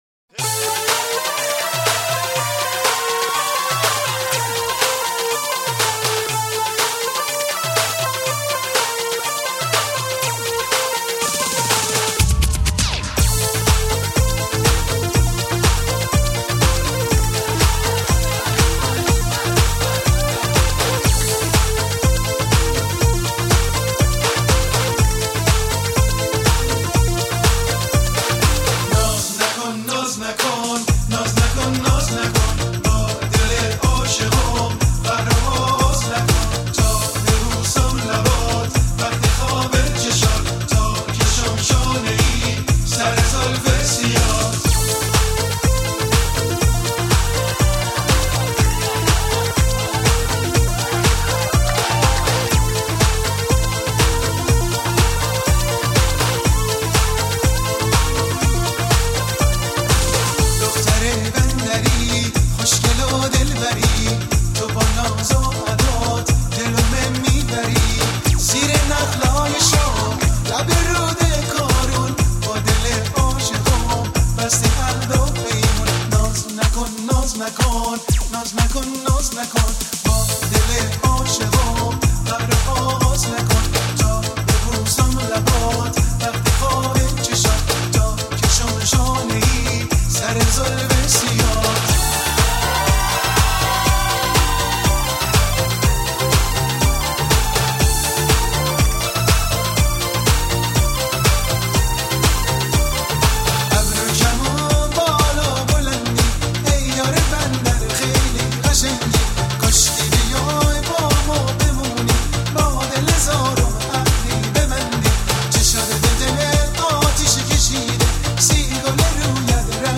ژانر: پاپ
توضیحات: ریمیکس شاد ترانه های قدیمی و خاطره انگیز